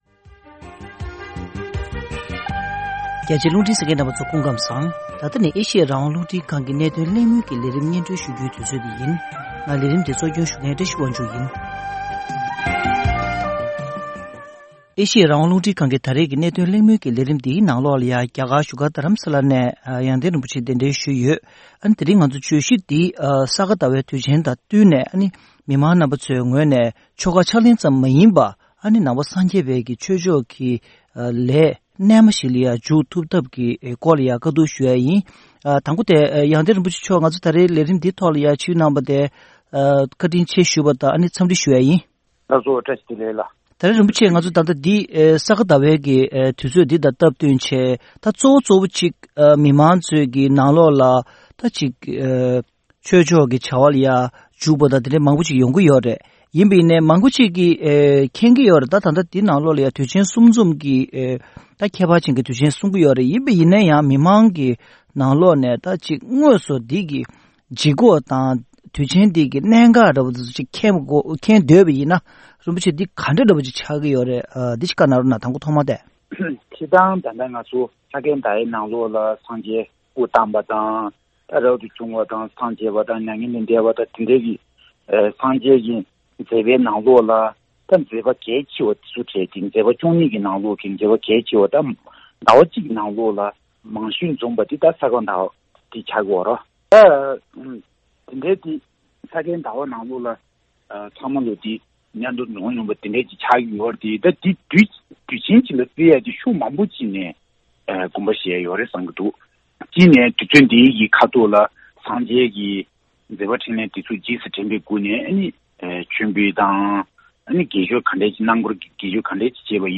གླེང་མོལ།